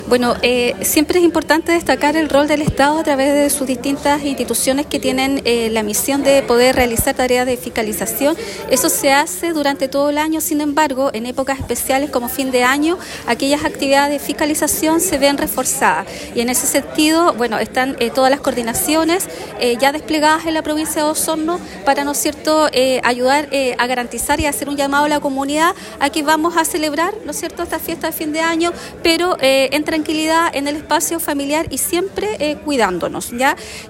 La Delegada Presidencial Provincial, Claudia Pailalef, informó que las instituciones del Estado están reforzando las fiscalizaciones en múltiples áreas, que van desde el control del tránsito hasta la prevención de delitos.